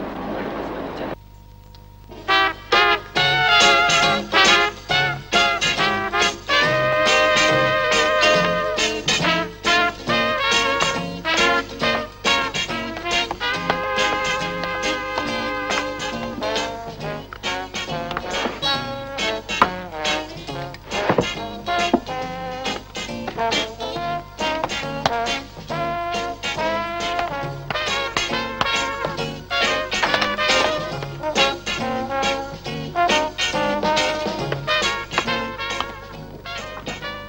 Что за мелодия звучит в конце первой серии советского телесериала Совесть ( примерно на 67мин.30сек.)песня на английском языке и еще там две очень веселые танцевальные инструментальные композиции ( на 69 мин.50сек. и 75мин.00сек )Помогите пожалуйста!